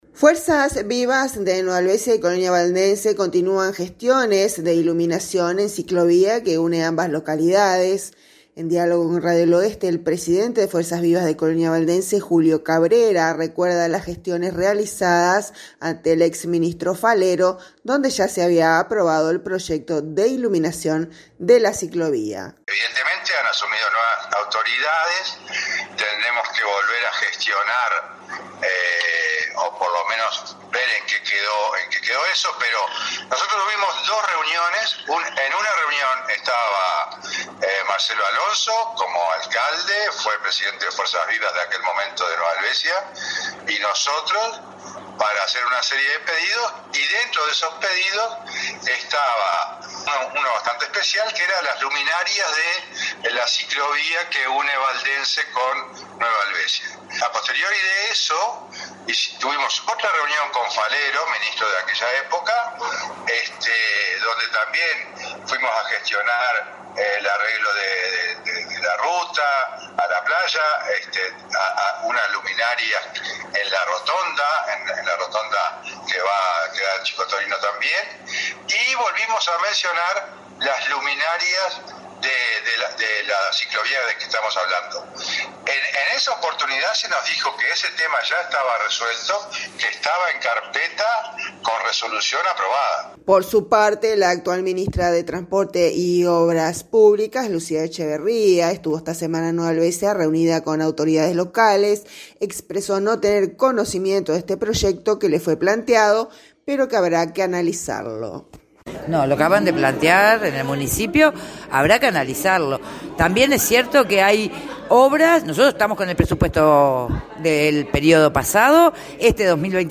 En diálogo con Radio del Oeste